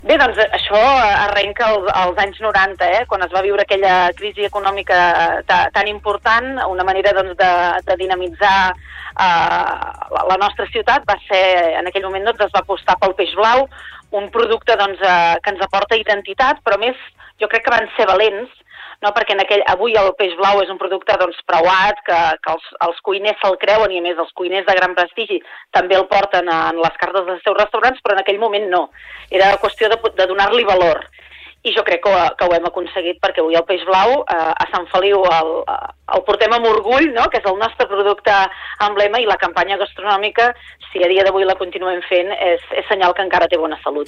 Supermatí - entrevistes
Per parlar-nos d’aquestes dues propostes ens ha visitat la regidora de turisme de Sant Feliu de Guíxols, Núria Cucharero.